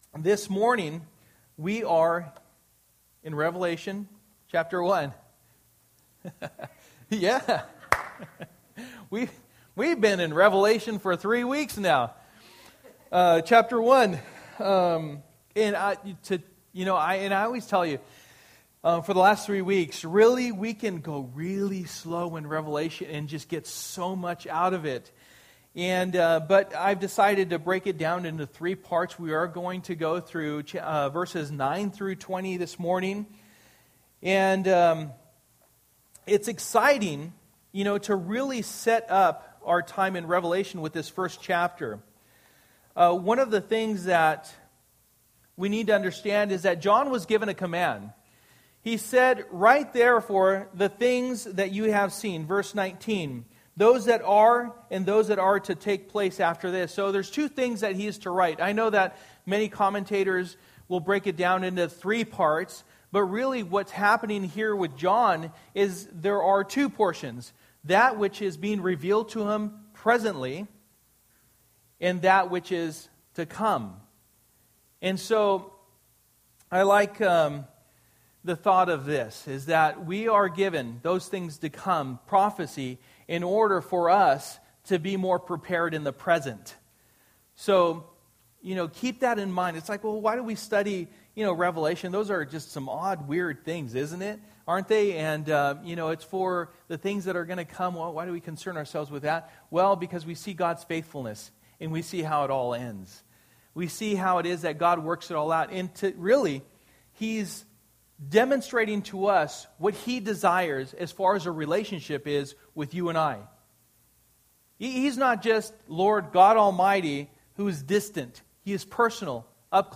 For the Time is Near Passage: Revelation 1:9-20 Service: Sunday Morning %todo_render% Download Files Bulletin « Genesis 43 So What are Your Convictions?